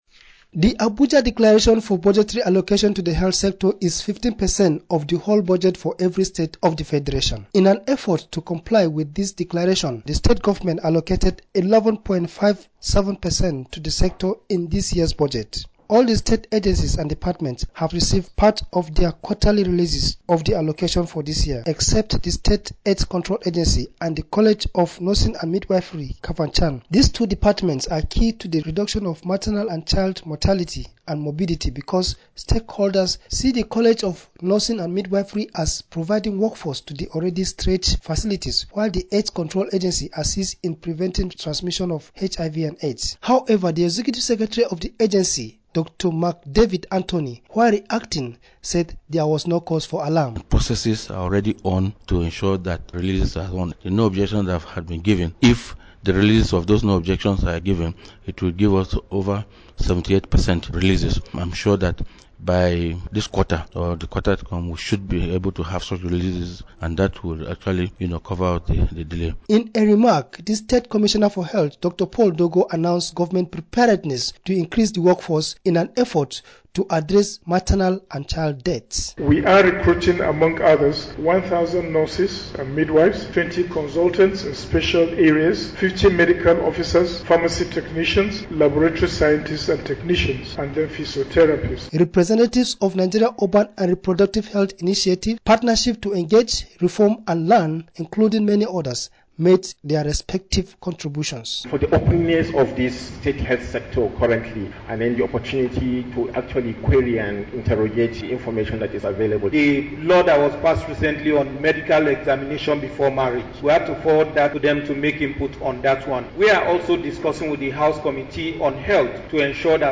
Radio Report: Maternal and child health in Kaduna
During a one-day forum organised by the Kaduna State Maternal New Born and Child Accountability Mechanism in conjunction with Community Health Research and Reform Initiative, participants said the subdivisions are very essential to the health of women and children.